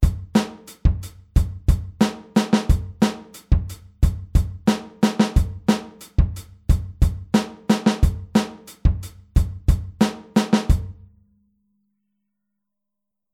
Anders als die Wechselschläge bei 16tel spielt die rechte Hand durchgängig alle 8tel.
Groove21-8tel.mp3